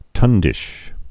(tŭndĭsh)